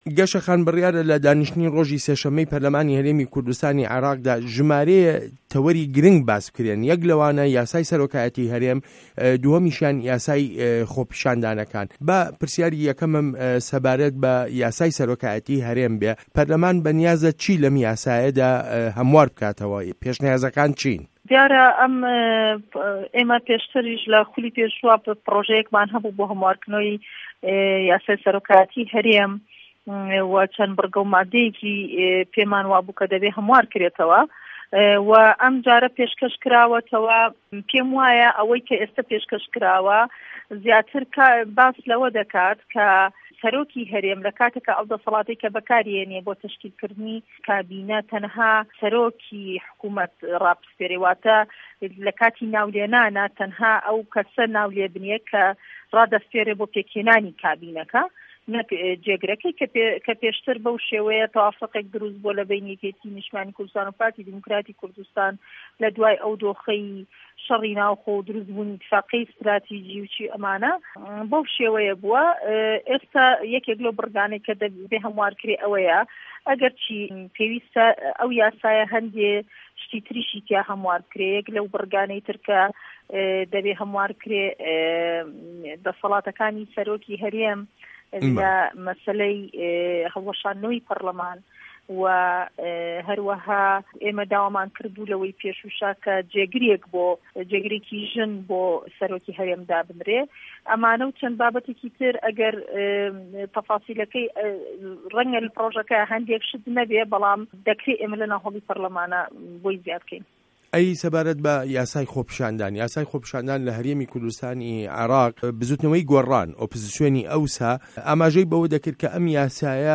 وتووێژ له‌گه‌ڵ گه‌شه‌ دارا حه‌فید